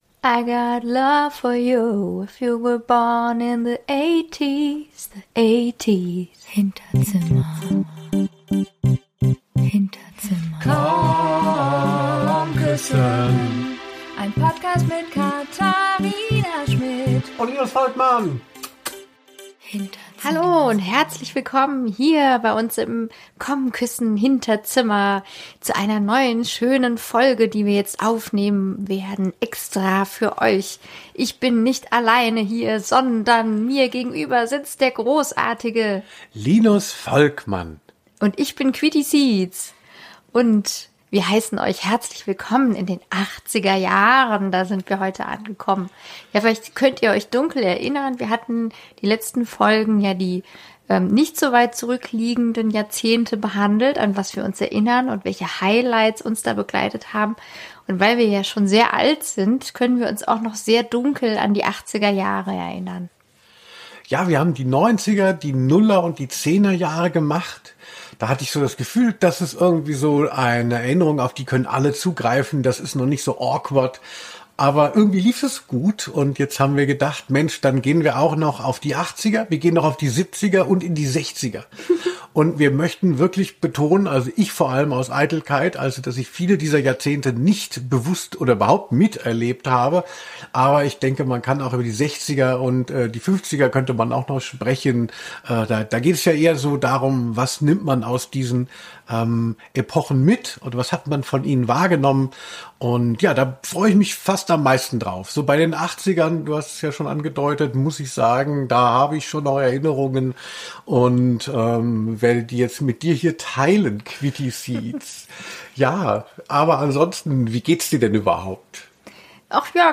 Diese Aufnahme datiert vor unserer Aussprache, hier klinge ich noch sehr underwhelmed, wenn ich über unsere Beziehung spreche.